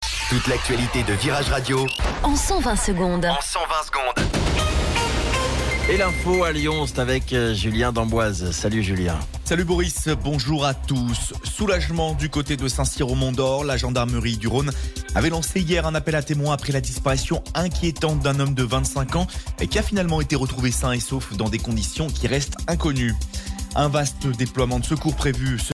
Flash Info Lyon